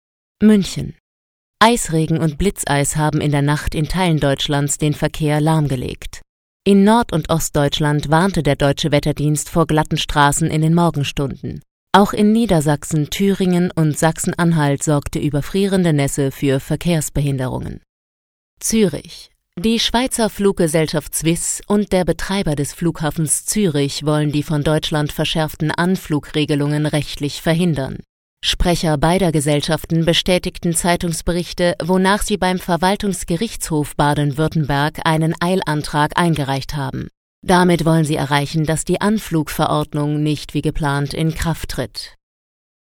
facettenreich,dynamisch, kräftig, schrill, kindlich, lieblich, verführerisch, warm, Schauspielerin, Sängerin
Sprechprobe: Werbung (Muttersprache):